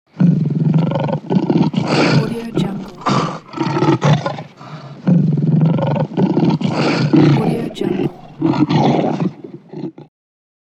Lion Growls Efecto de Sonido Descargar
Lion Growls Botón de Sonido